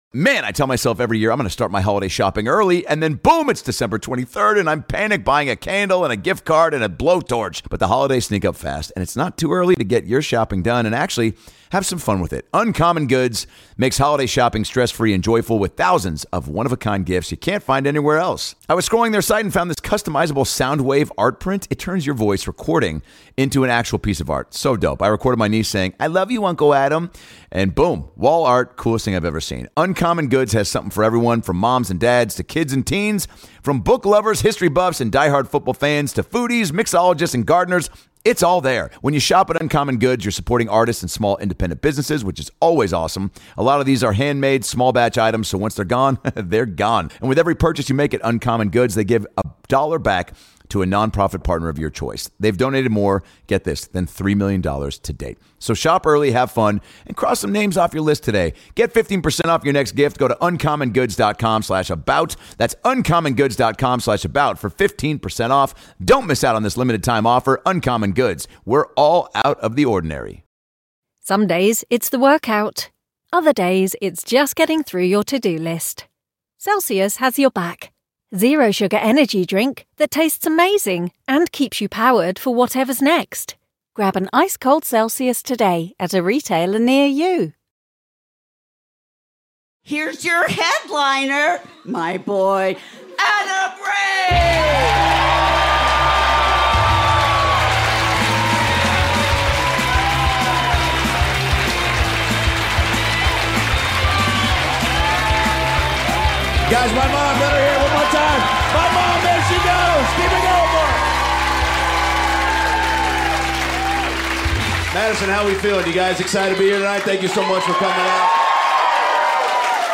This is it, Adam Ray's brand new standup comedy special!
Filmed on location at Comedy On State in Madison, Wisconsin